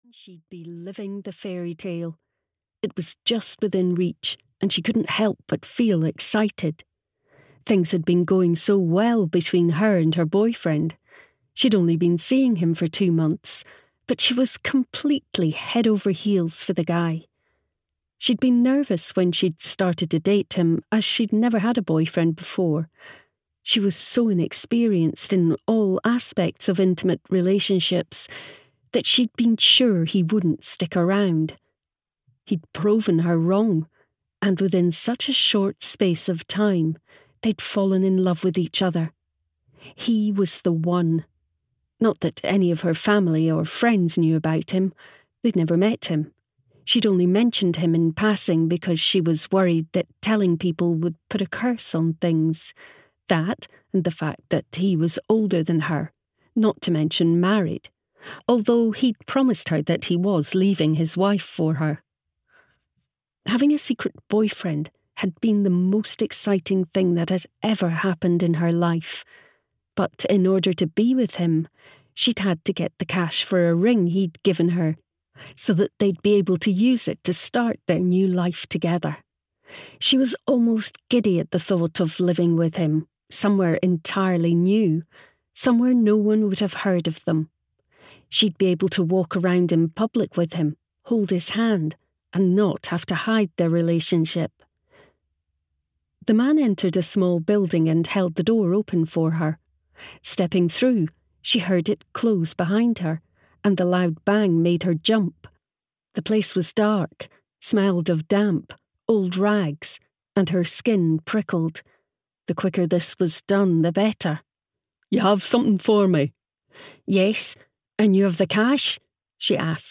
The Family Business (EN) audiokniha
Ukázka z knihy